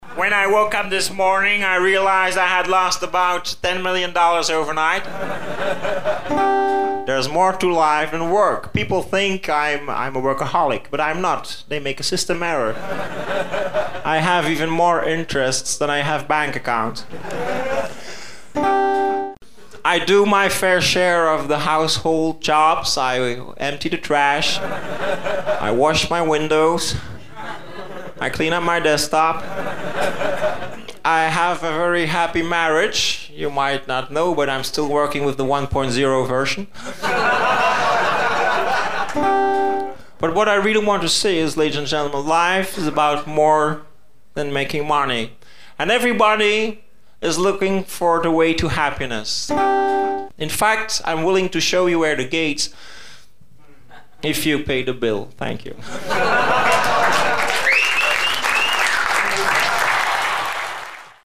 Bill Gates (Samples from the sketch, English)